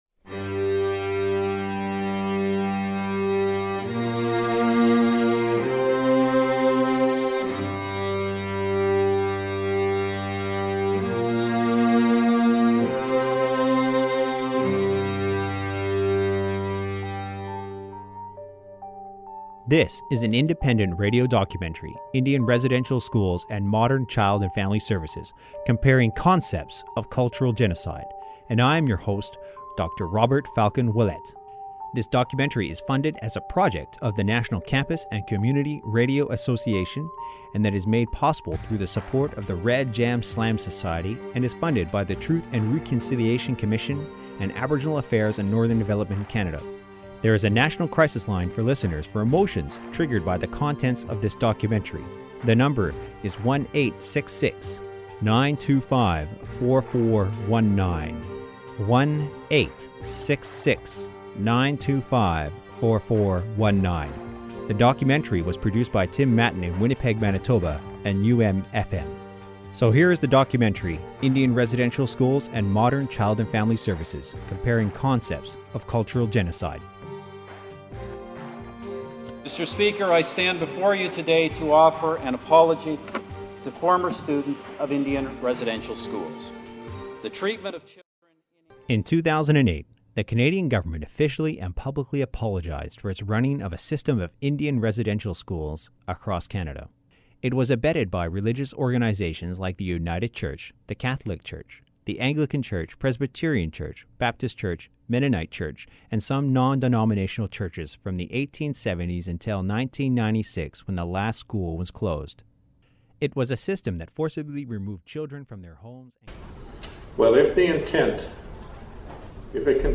Recording Location: winnipeg
Status: As Broadcast
Type: Documentary